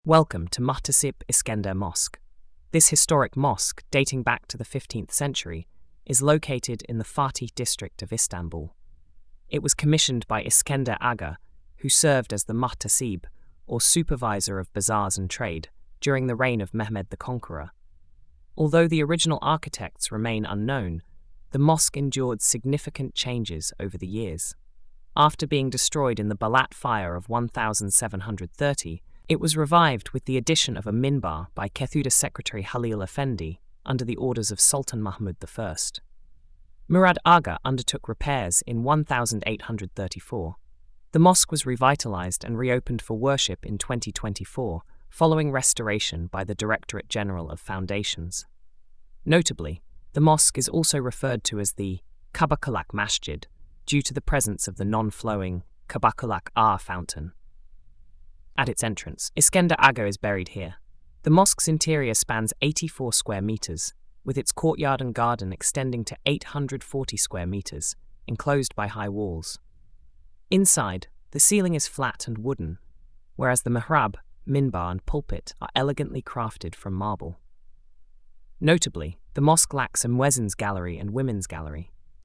Audio Narration